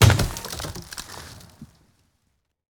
car-tree-wood-impact-03.ogg